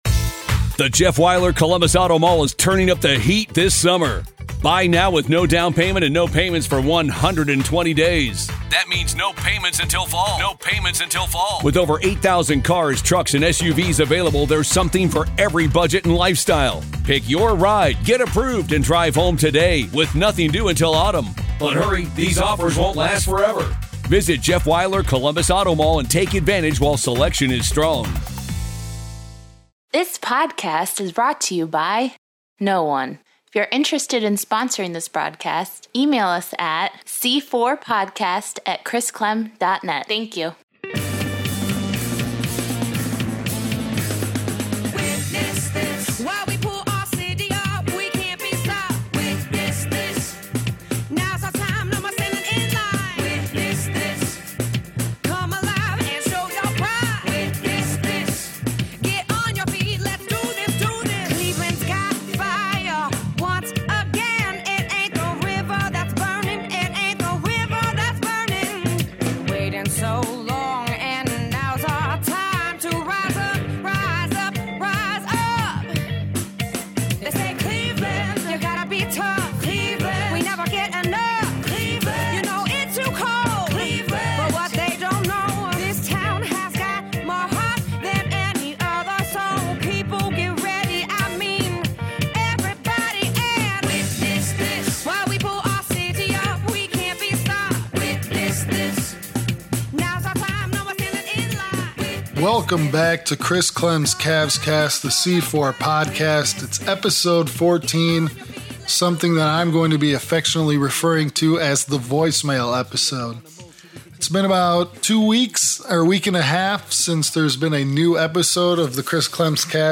Catch up and kick back with some voicemails!